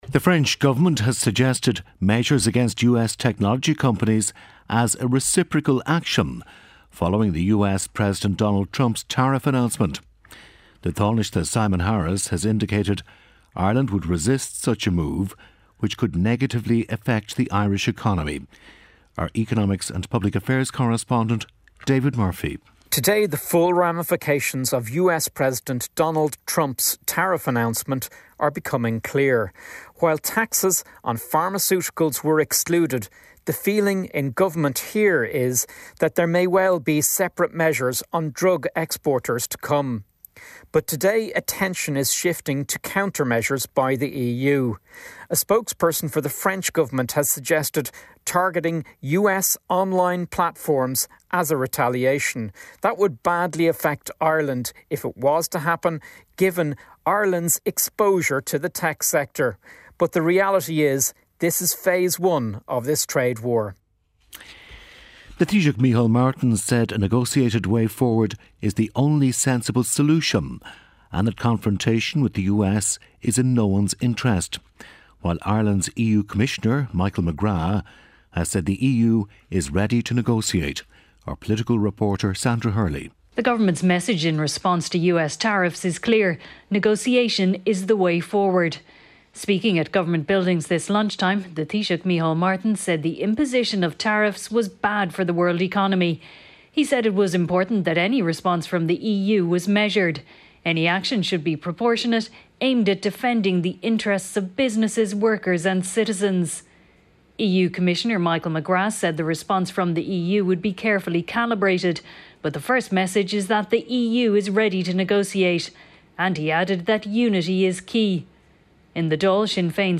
1pm News Bulletin - 03.04.2025